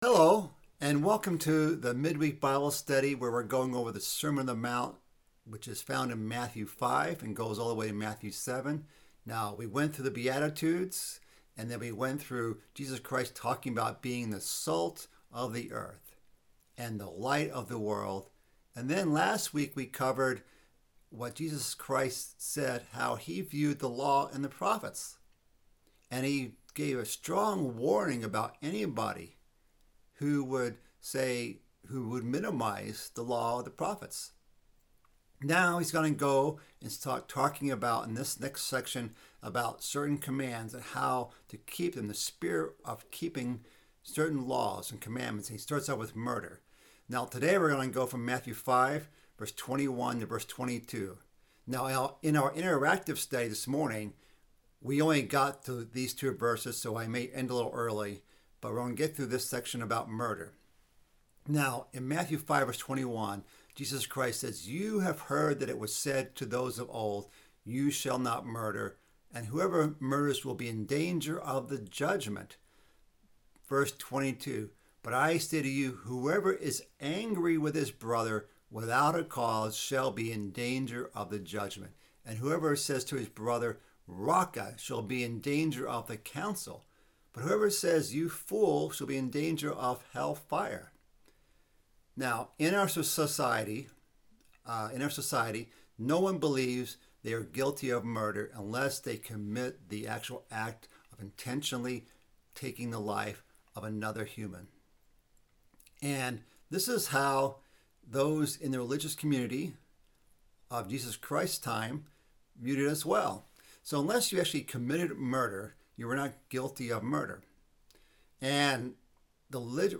This is part of a mid-week Bible study series covering the sermon on the mount. This week's study delves into the connection between anger and murder, and our command as Christians to not allow that anger into our lives.